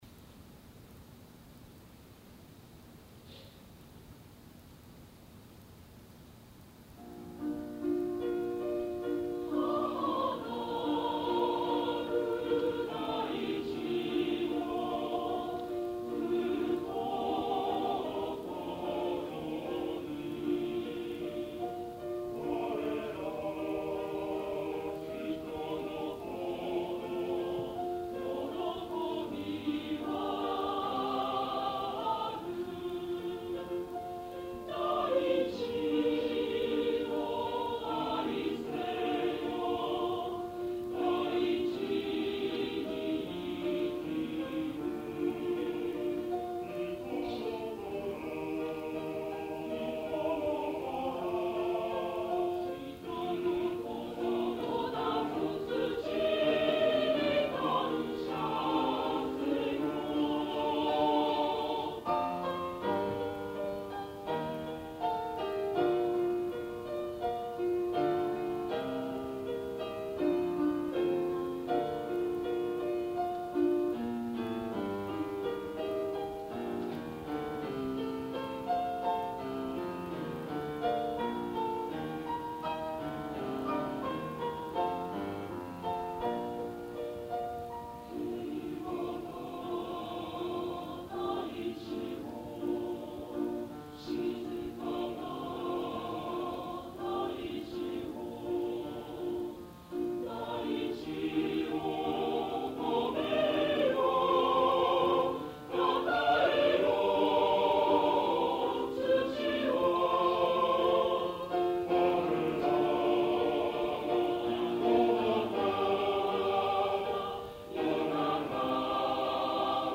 １９９９年６月１３日　東京・中野ゼロホールで
ソプラノ５名、アルト５名、テナー４名、ベース３名の
編成です。男性１名カウンターテナーがアルトです。
お母様が小さなラジカセでテープ録音をして頂き、
合唱曲の定番
合唱団　某大学ＯＢ会合唱団メンバー　１７名